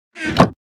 Minecraft Version Minecraft Version snapshot Latest Release | Latest Snapshot snapshot / assets / minecraft / sounds / block / chest / close1.ogg Compare With Compare With Latest Release | Latest Snapshot